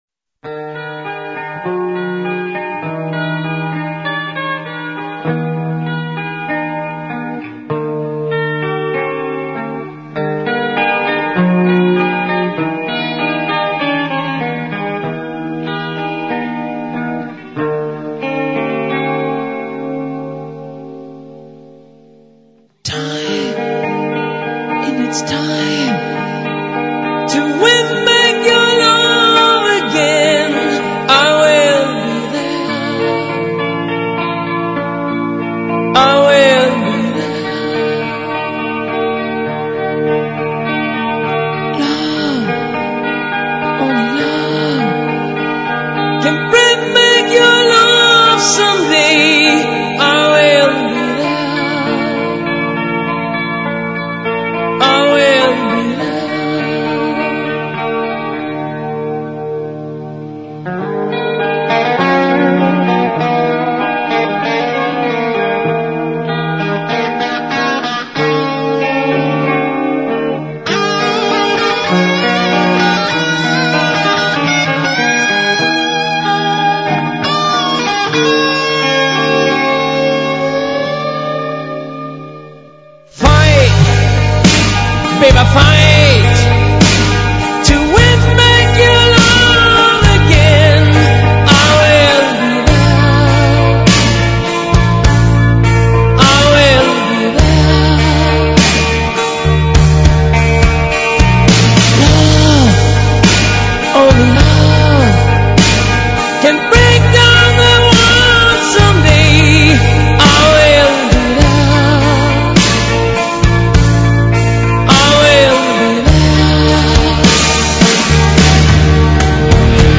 Качество очень даже ничего[2220кб]